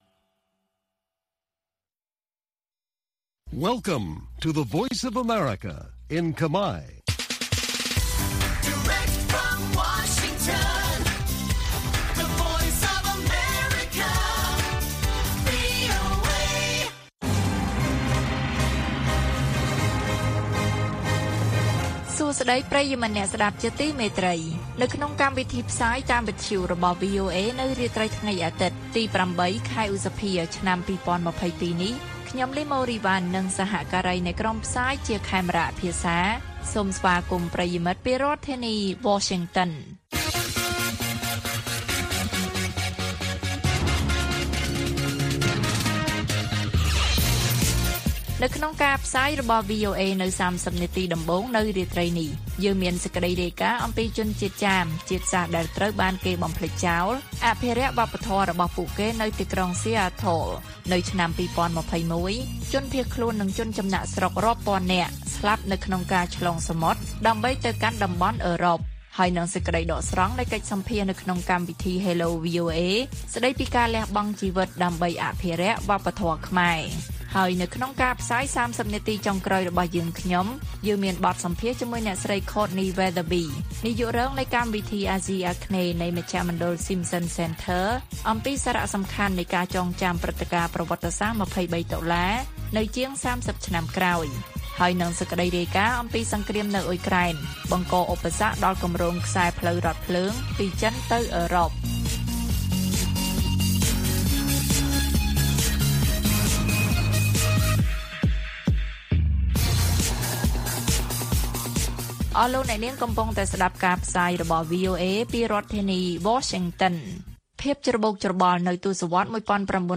ព័ត៌មាននៅថ្ងៃនេះមានដូចជា ជនជាតិចាម ដែលត្រូវបានគេបំភ្លេចចោល អភិរក្សវប្បធម៌របស់ពួកគេនៅទីក្រុង Seattle។ សេចក្តីដកស្រង់នៃកិច្ចសម្ភាសន៍នៅក្នុងកម្មវិធី Hello VOA ស្តីពី«ការលះបង់ជីវិតដើម្បីអភិរក្សវប្បធម៌ខ្មែរ» និងព័ត៌មានផ្សេងទៀត៕